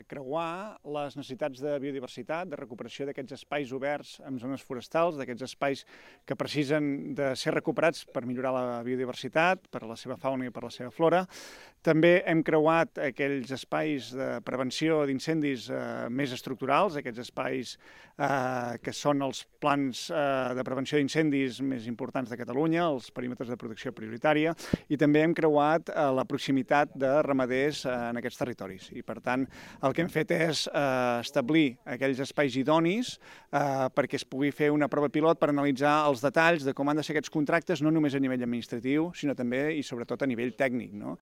La selecció d’aquestes zones, tal com explica el director general de Polítiques Ambientals i Medi Natural, Marc Vilahur – en declaracions a ACN – respon al creuament de diversos plans per determinar en quin espai feia falta una gestió de la massa forestal. Per això, s’han tingut en compte aspectes com el pla de prevenció d’incendis i, també, la conservació de la biodiversitat, especialment en espais oberts en zones forestals.